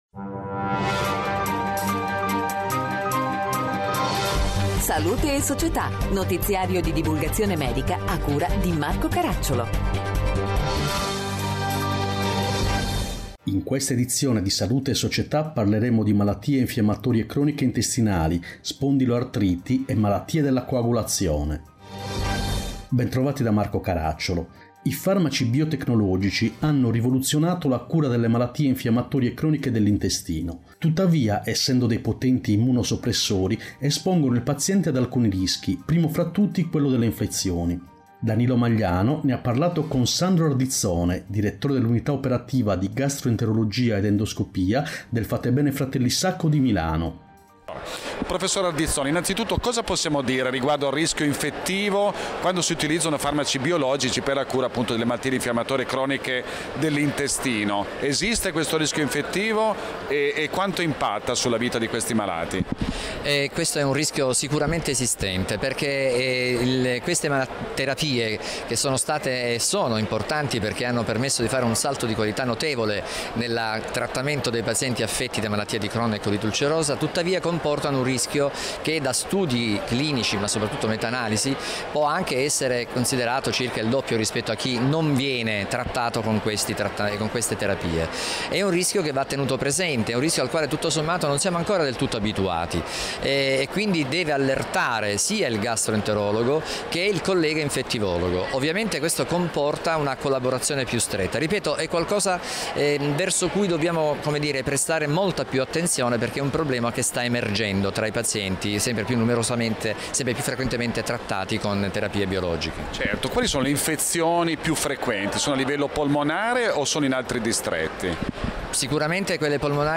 In questa edizione: 1. MICI, Farmaci biotecnologici e rischio infezioni 2. Spondiloartriti, Qualità della vita 3. Malattie della coagulazione, Le ultime novità terapeutiche Interviste